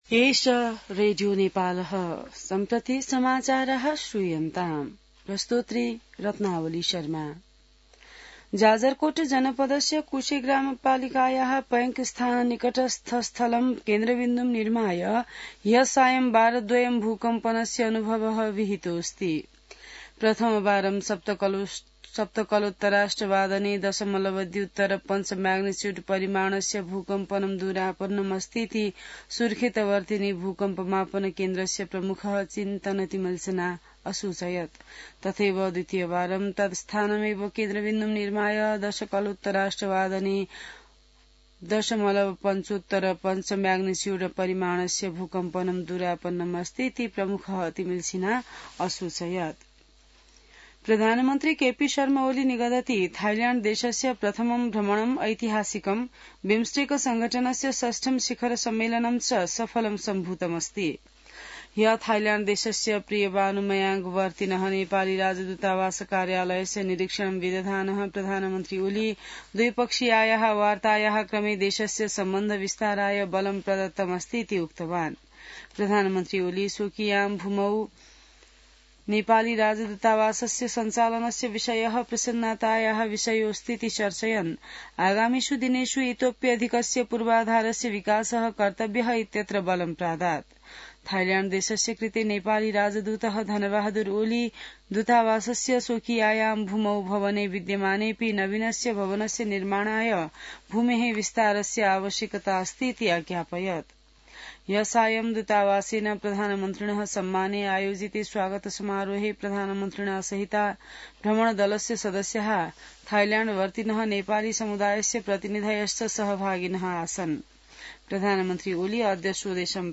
संस्कृत समाचार : २३ चैत , २०८१